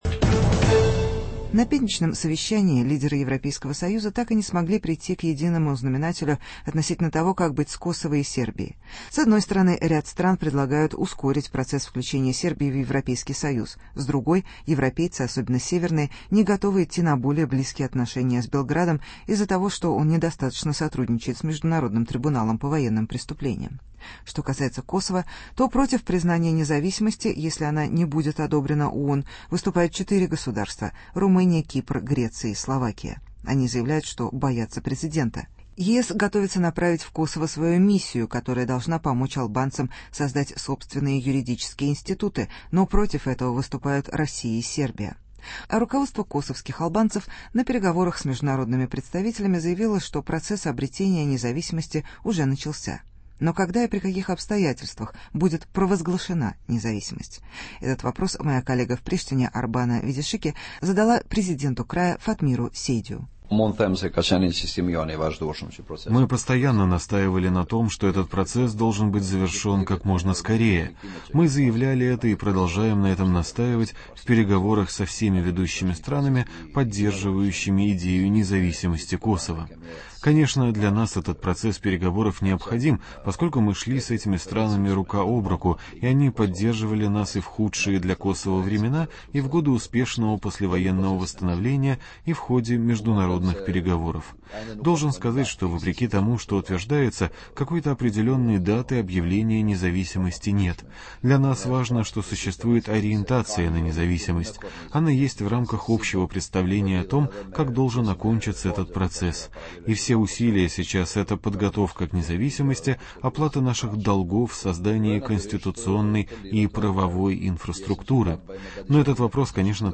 Интервью с президентом Косова